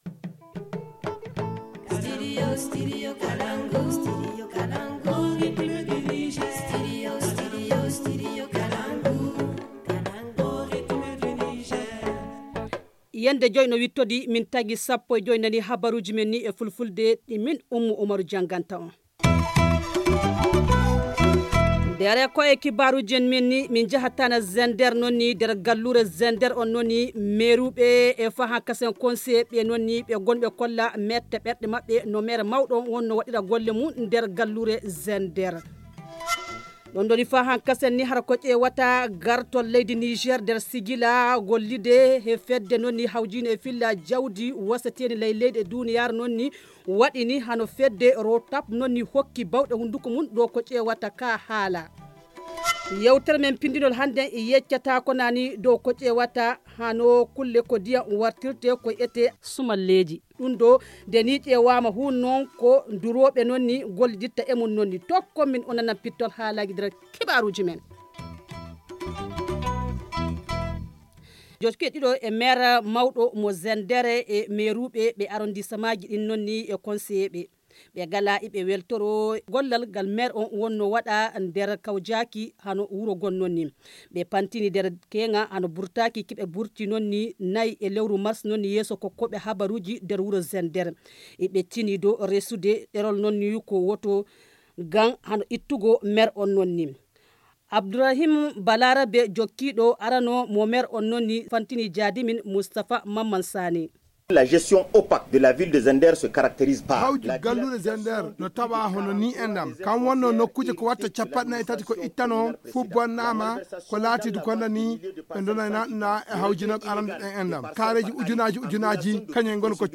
Le journal du 05 mars 2020 - Studio Kalangou - Au rythme du Niger